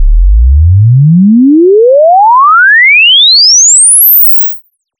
sweep.wav